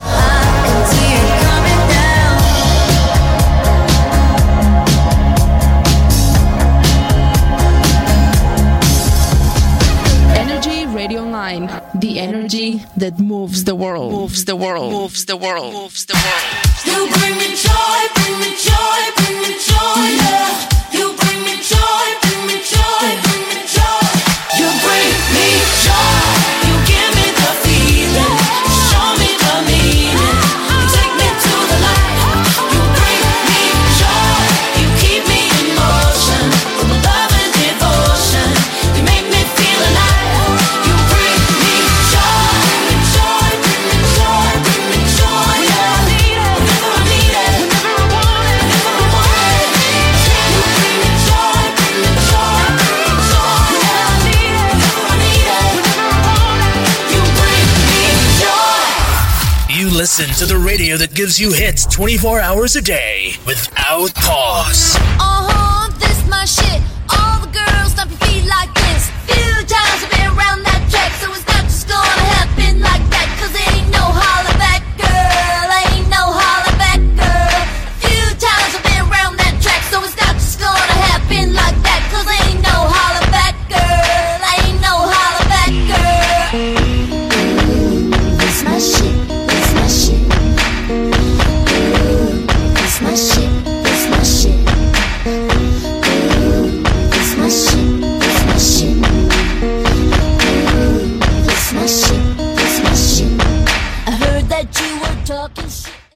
Tema musical, identificació, tema musical, identificació i tema musical Gènere radiofònic Musical